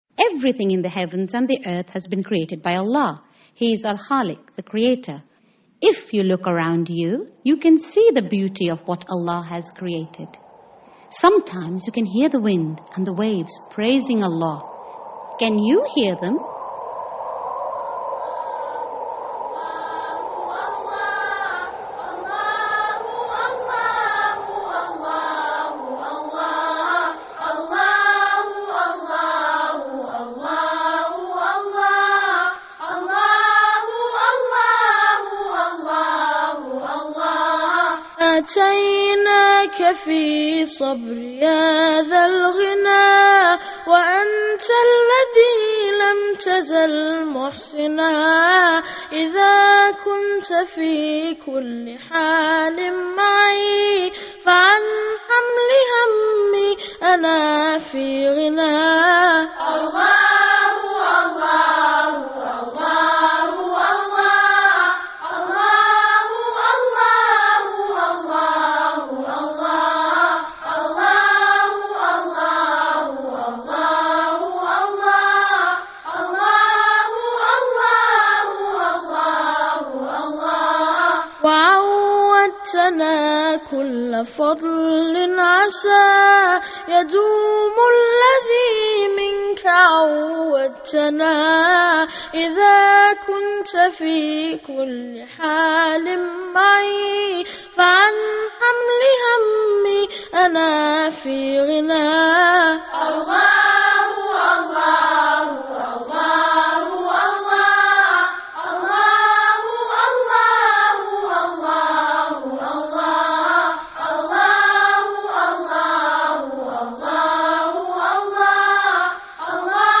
lively and inspirational songs for children